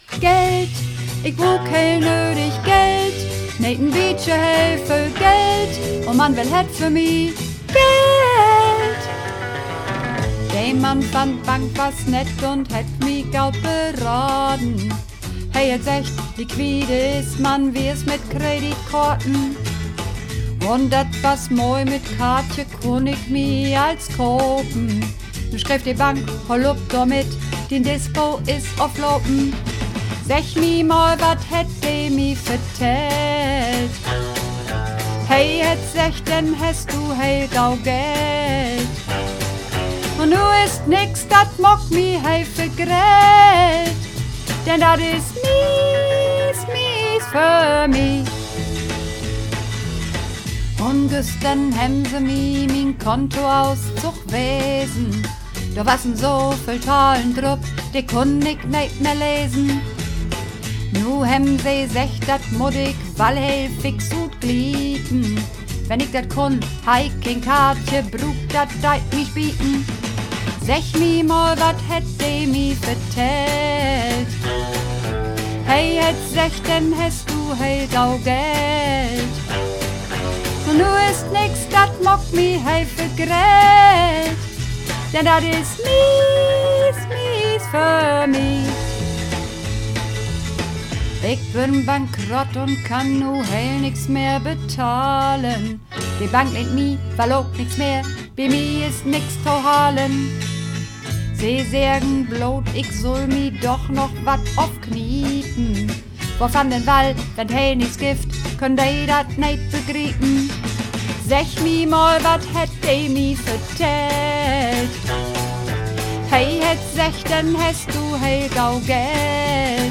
Übungsaufnahmen - Geld
Geld (Sopran)
Geld__3_Sopran.mp3